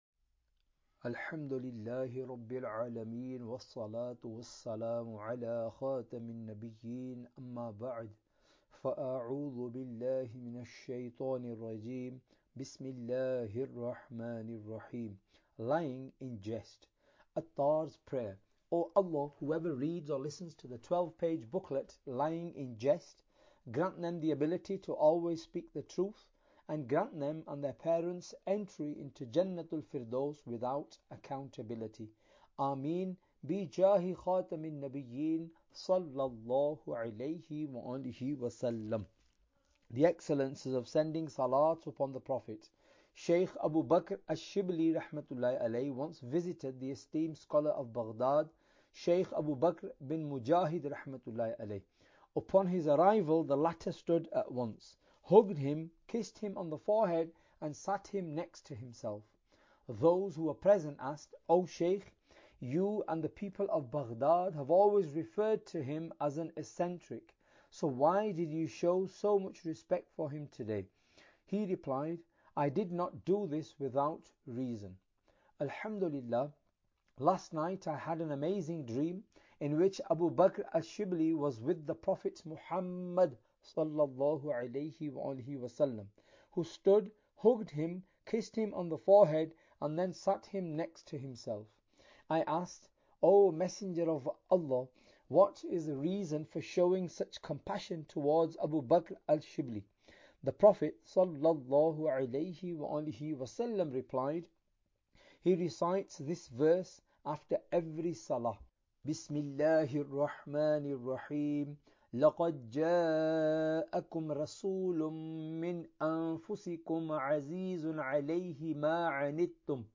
Audiobook – Lying In Jest (Engliah)